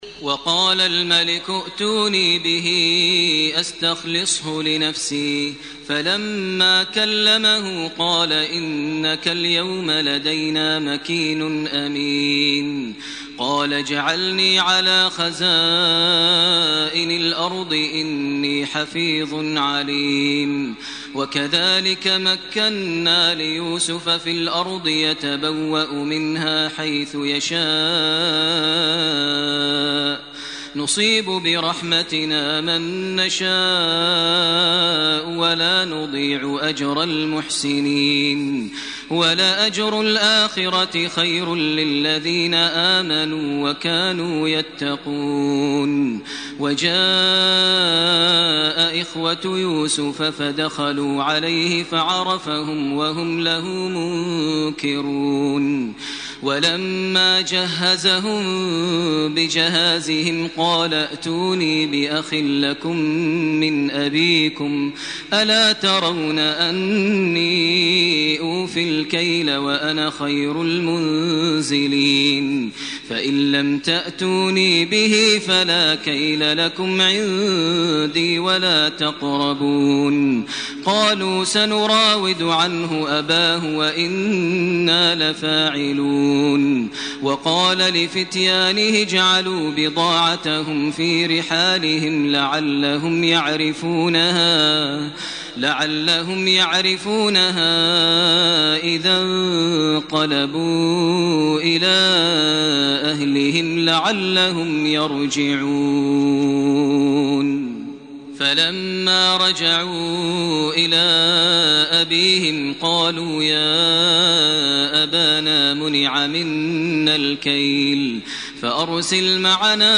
سورة يوسف من 54 الي اخرها سورة الرعد من 1 الي 18 > تراويح ١٤٢٨ > التراويح - تلاوات ماهر المعيقلي